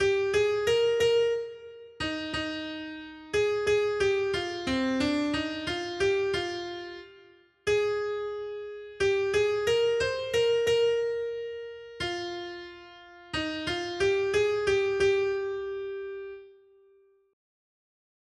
Noty Štítky, zpěvníky ol438.pdf responsoriální žalm Žaltář (Olejník) 438 Skrýt akordy R: Blaze tomu, kdo svou naději vložil do Hospodina. 1.